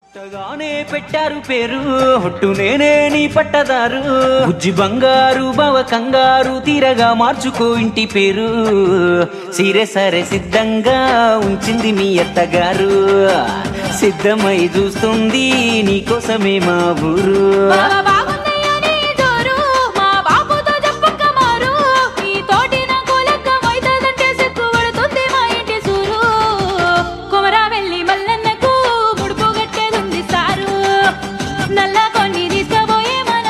love song ringtone
dance ringtone download